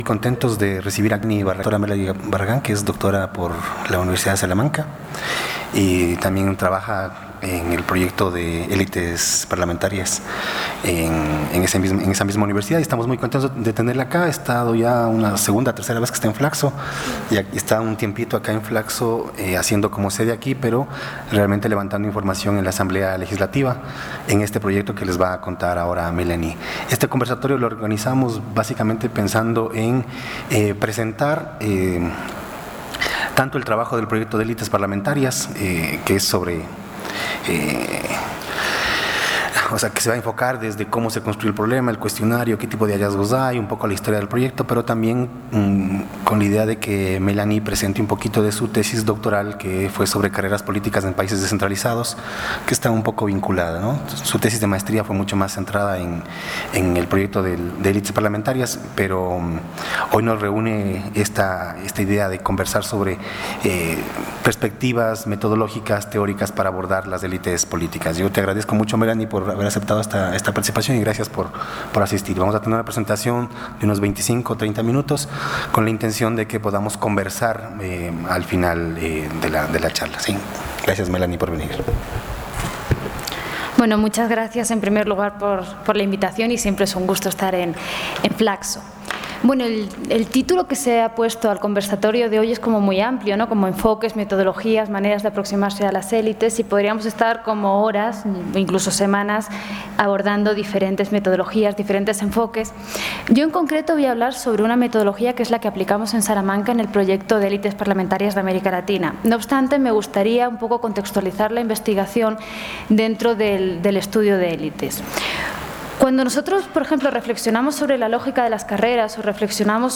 Conversatorio ¿Cómo estudiar las élites políticas? problema